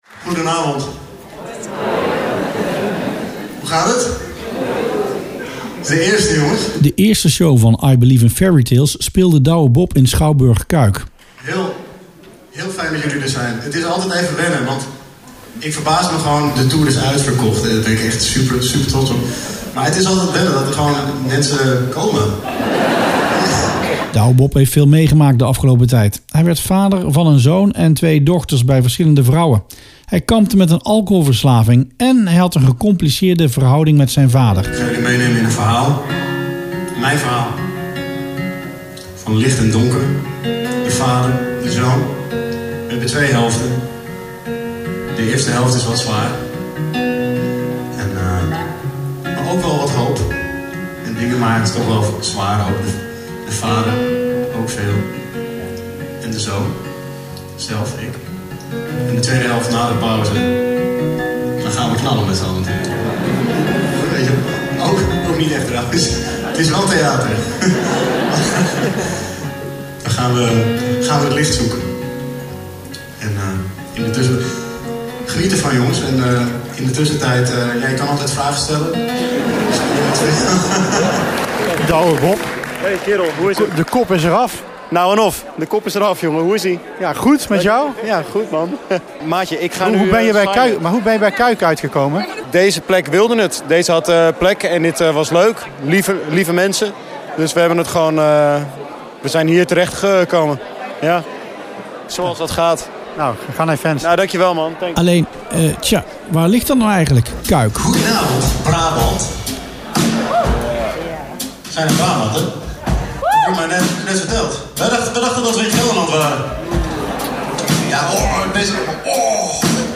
Reportage voor Rustplaats Lokkant